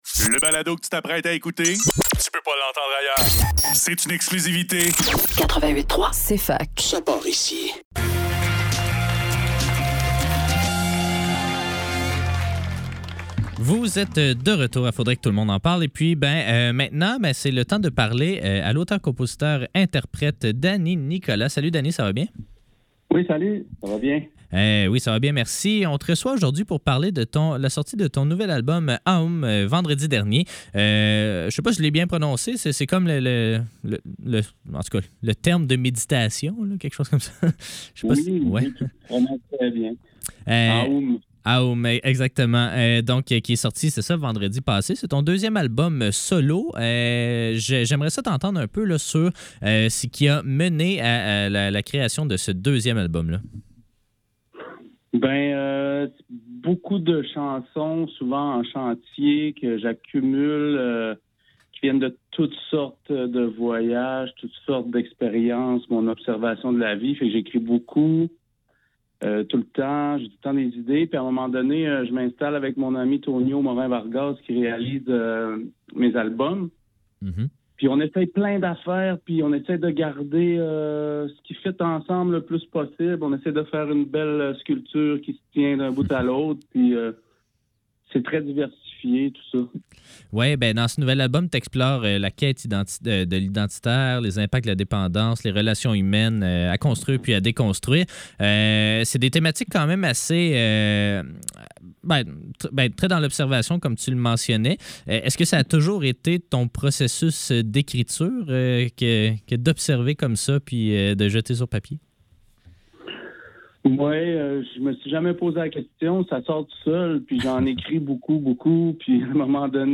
Faudrait que le tout l'monde en parle - Entrevue